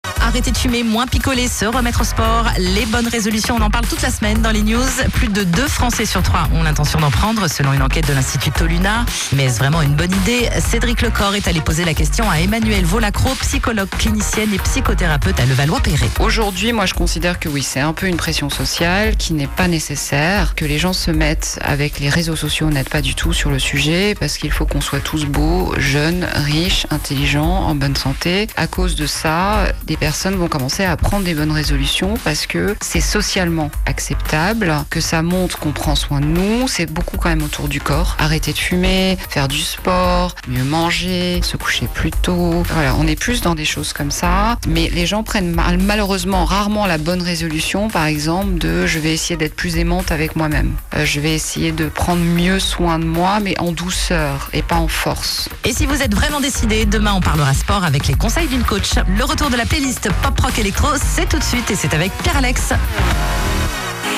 Interview pour « les news »